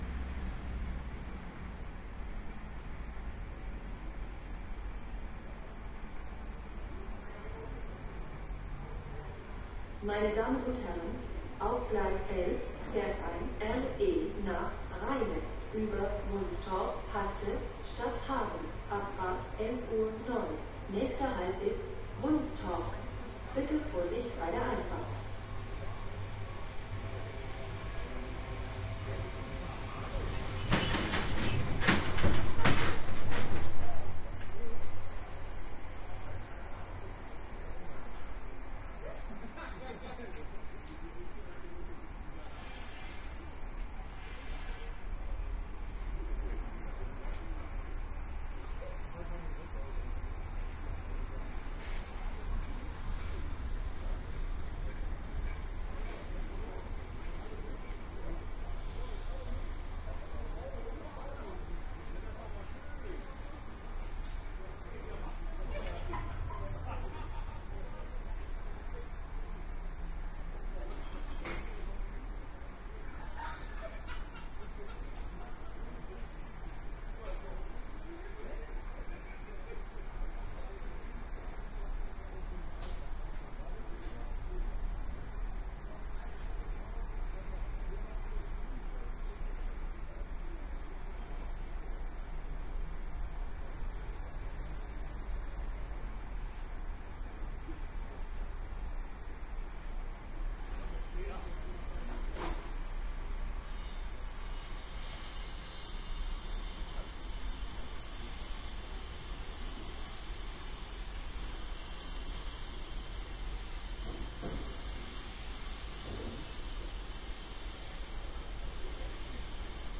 荷兰的火车和车站 " 内部火车启动巡航和停止 - 声音 - 淘声网 - 免费音效素材资源|视频游戏配乐下载
记录荷兰电动火车内的火车。开始，巡航和停止。